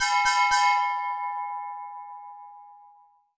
auction bell2.wav